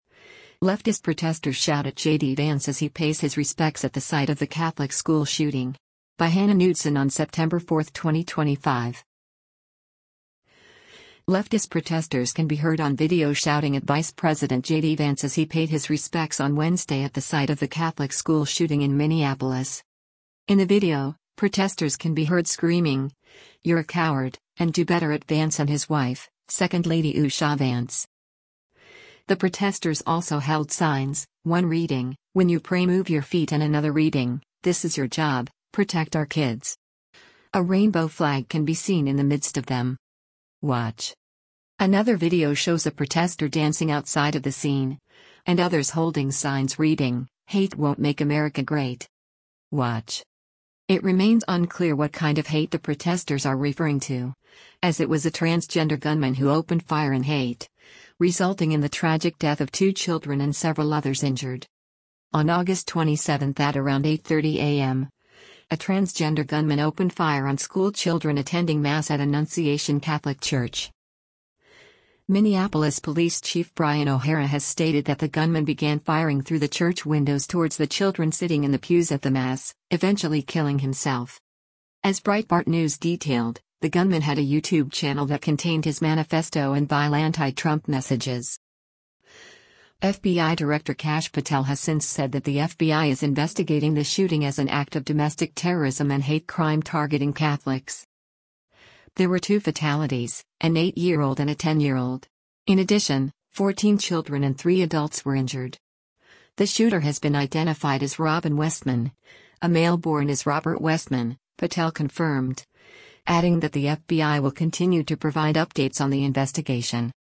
Leftist protesters can be heard on video shouting at Vice President JD Vance as he paid his respects on Wednesday at the site of the Catholic school shooting in Minneapolis.
In the video, protesters can be heard screaming, “You’re a coward,” and “do better” at Vance and his wife, second lady Usha Vance.